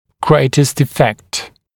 [‘greɪtəst ɪ’fekt][‘грэйтэст и’фэкт]самый большой эффект